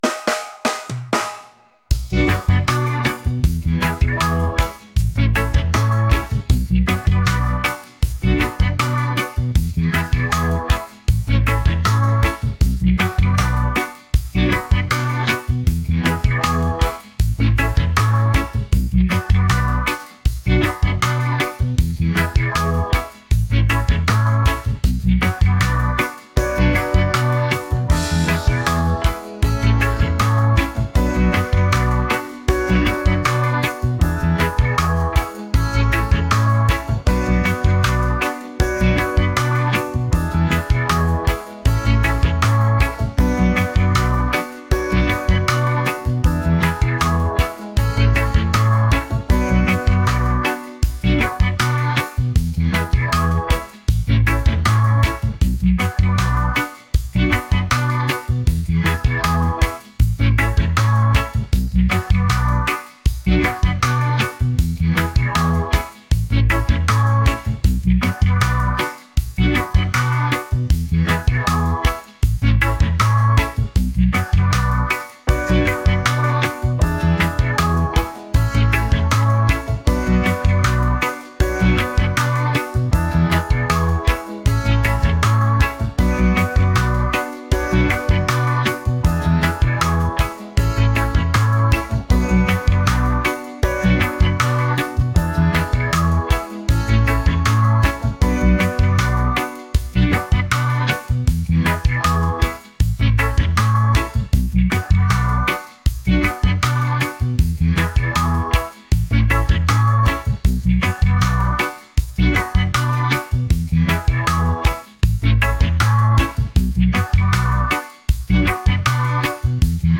reggae | groovy | rhythmic